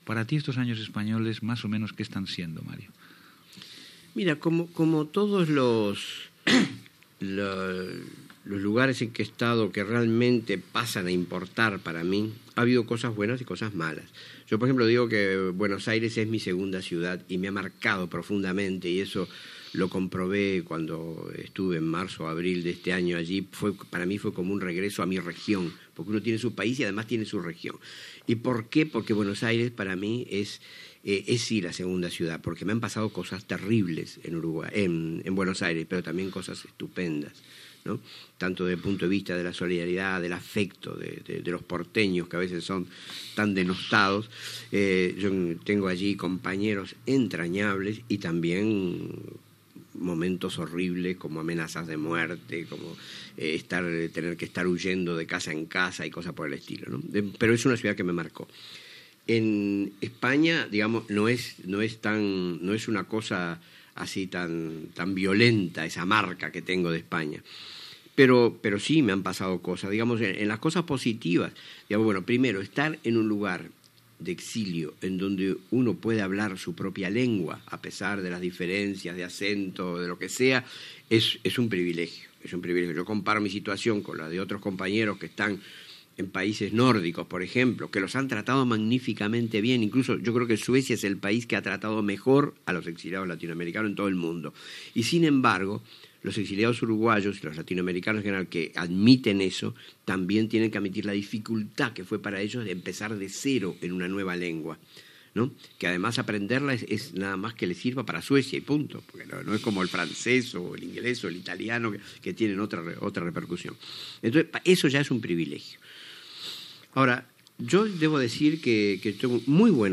Entrevista a l'escriptor Mario Benedetti sobre l'exili